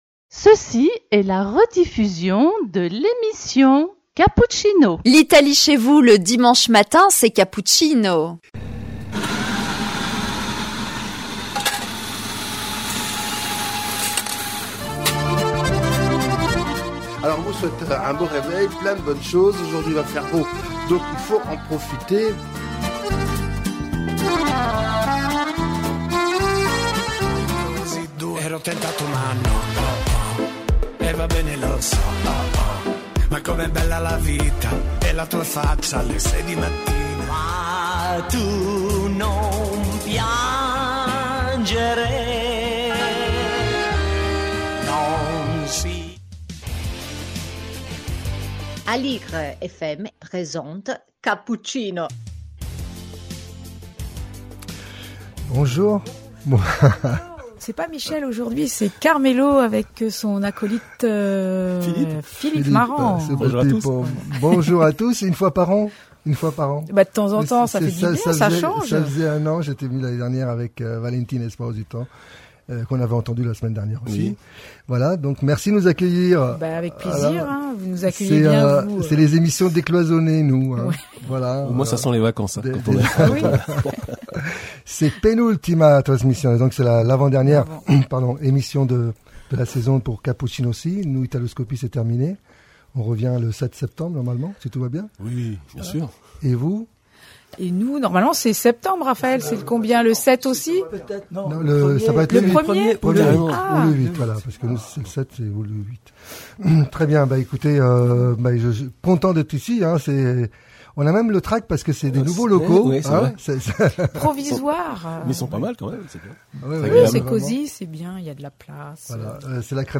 un programme de chansons italiennes et d'actualités variées